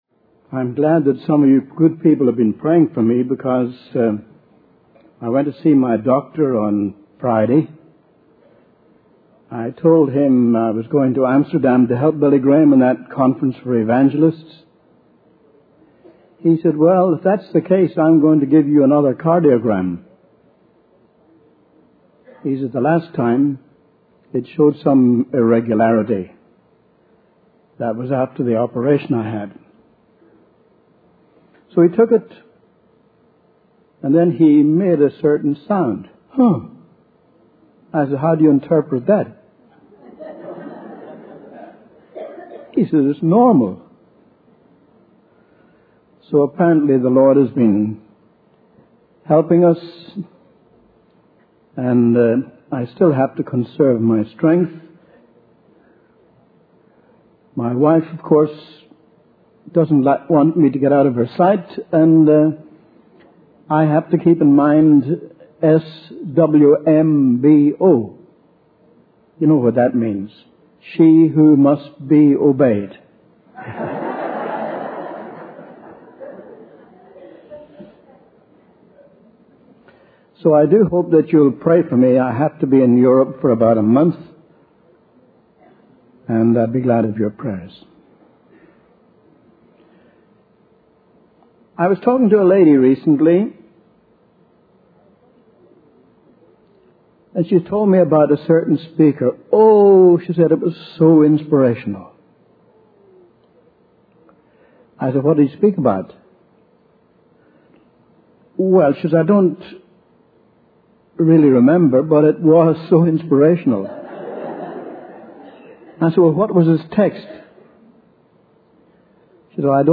In this sermon, the speaker emphasizes the importance of making the decision to become a Christian.